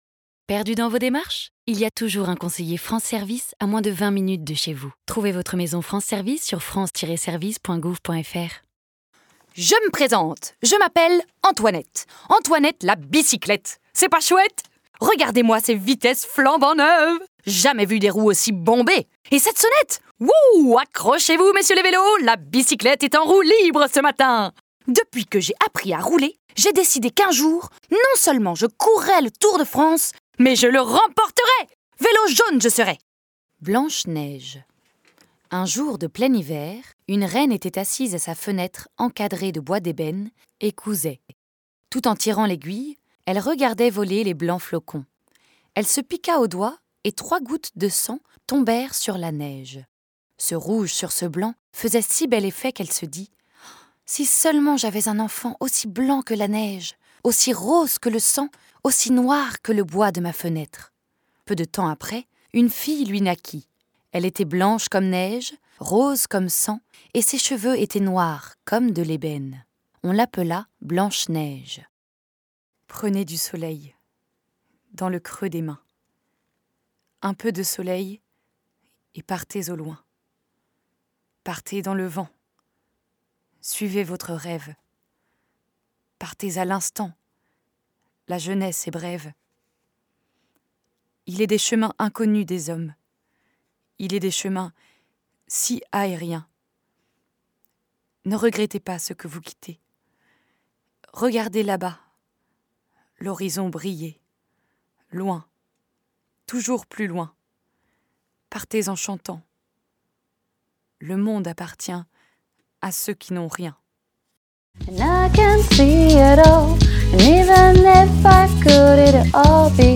Demo Voix
9 - 35 ans - Mezzo-soprano